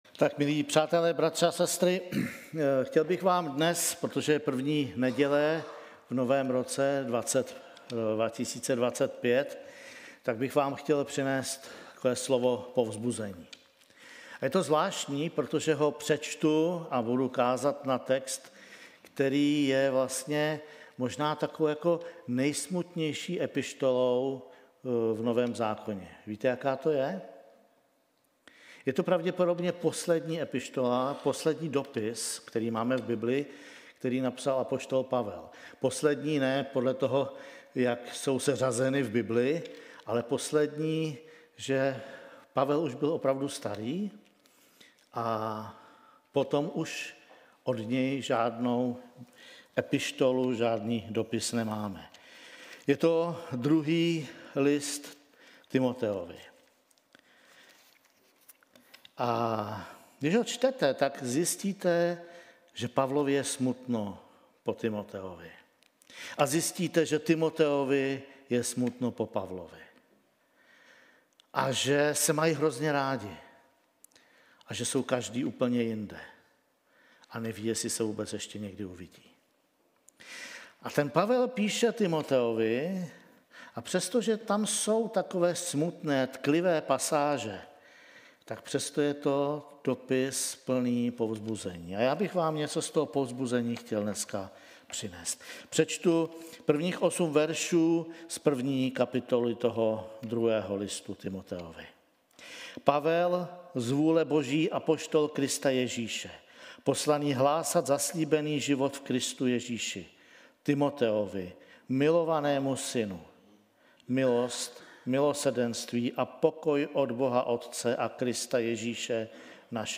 1 Kázání